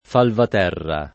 Falvaterra [ falvat $ rra ]